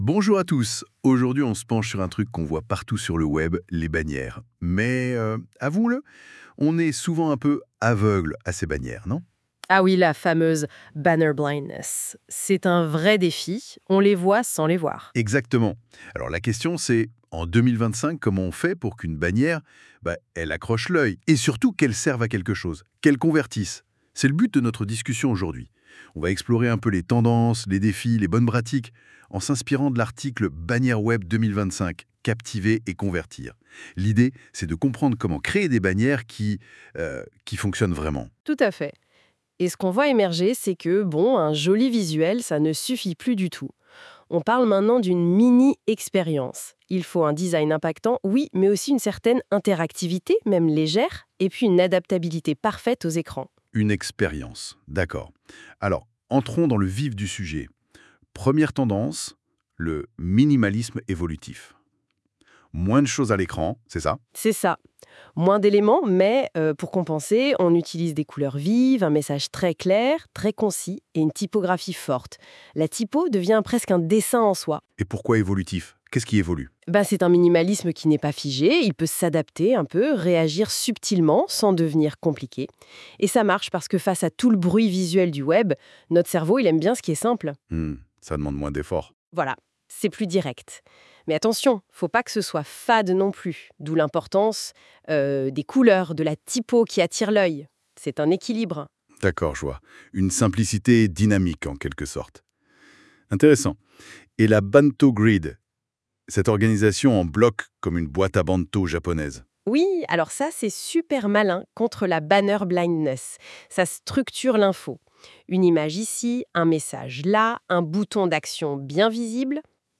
Nous avons intégré un podcast généré automatiquement grâce à NotebookLM, un outil d’intelligence artificielle.